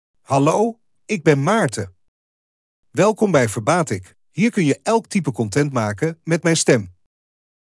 Maarten — Male Dutch AI voice
Maarten is a male AI voice for Dutch (Netherlands).
Voice sample
Listen to Maarten's male Dutch voice.
Maarten delivers clear pronunciation with authentic Netherlands Dutch intonation, making your content sound professionally produced.